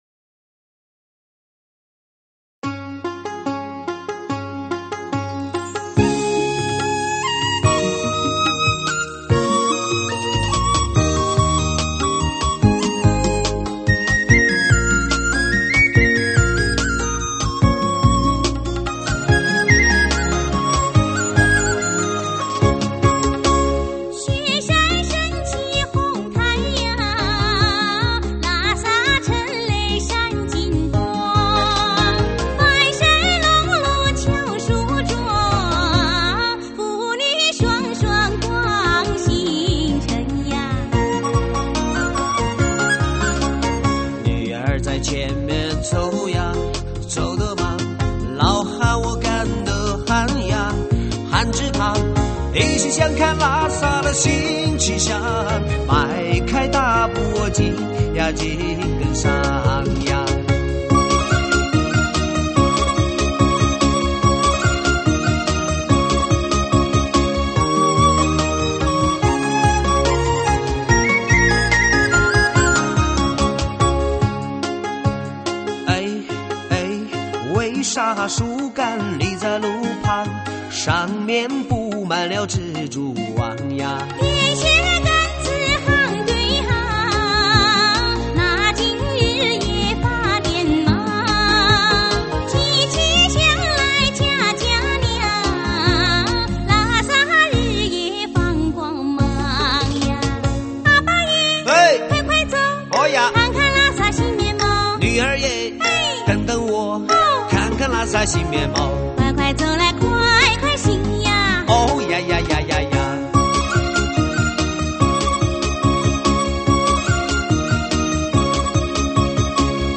6/9/2008]淳朴.洁净.自然的歌声--